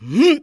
人声采集素材